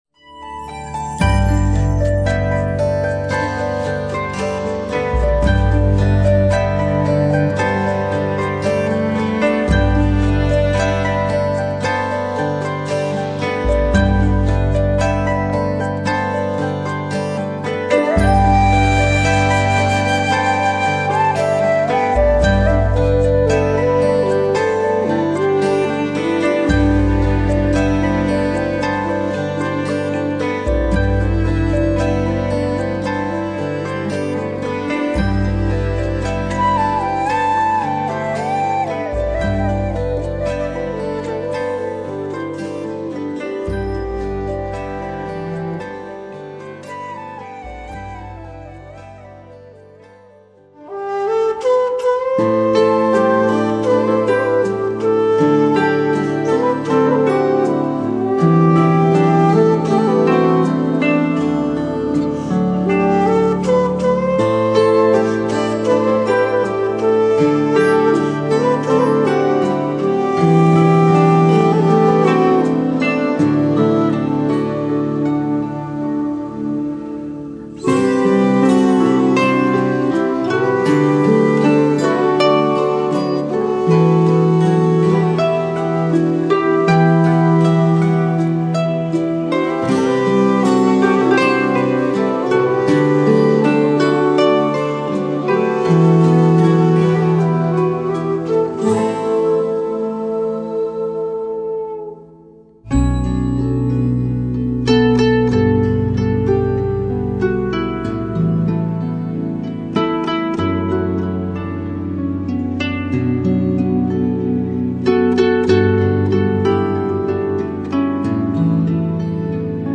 excerpts
Arch lute and Viola da gamba.